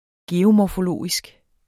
Udtale [ ˈgeːo- ]